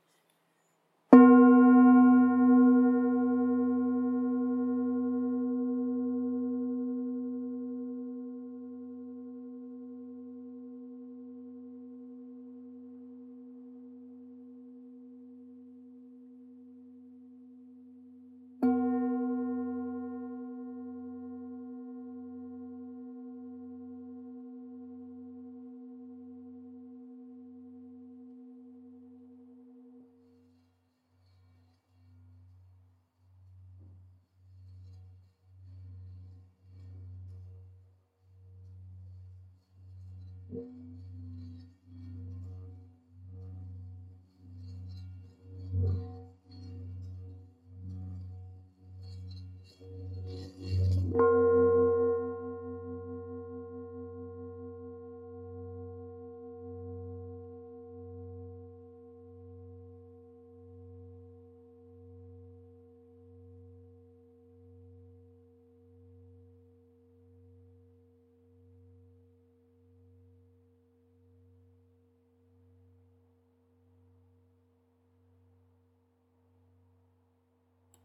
La sua nota FA risuona profondamente a circa 90 HZ.
Campana Tibetana ULTA Nota FA(F) 2 90 HZ R001 per yoga e meditazione
Campana Tibetana Antica 100-300 anni, creata con l’antica tradizione tibetana in lega dei 7 metalli.
Nota Armonica     SI(B) 3 252 HZ
Nota di fondo     FA(F) d2 90 HZ